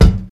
Kick (10).wav